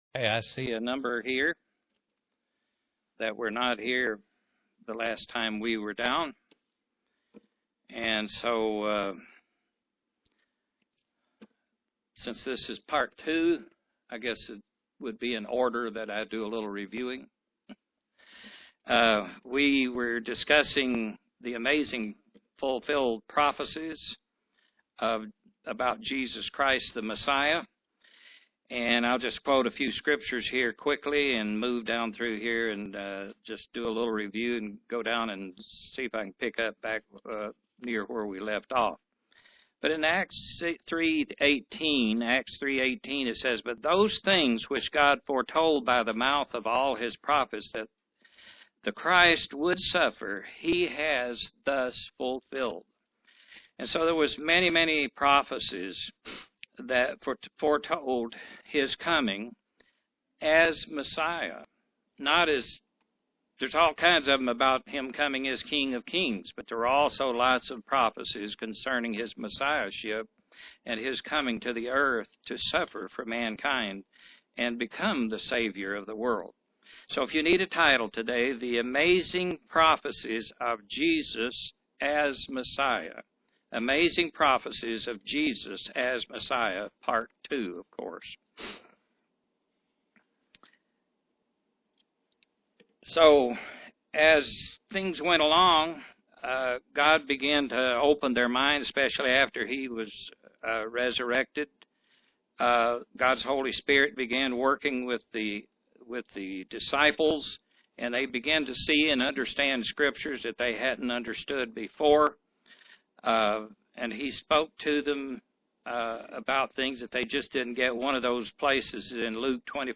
Given in Knoxville, TN
Print Prophecies concerning Christ shows prophecy fulfilled UCG Sermon Studying the bible?